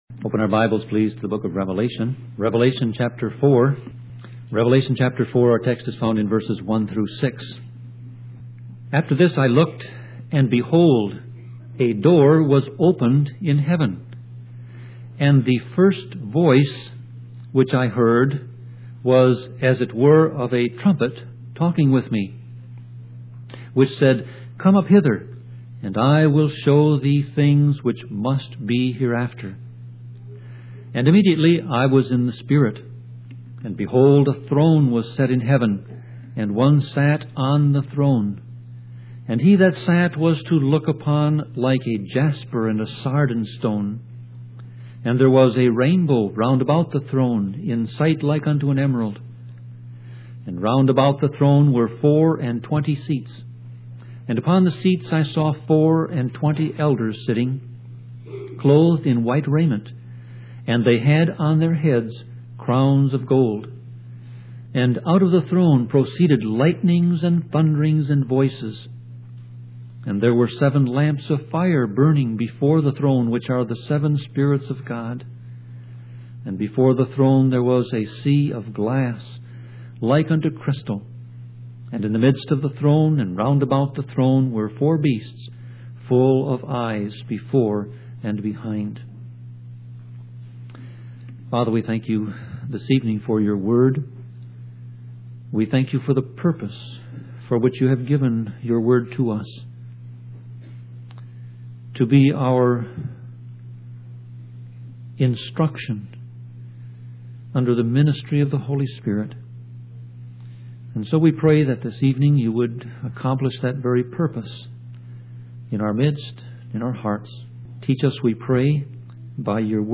Sermon Audio Passage: Revelation 4:1-6 Service Type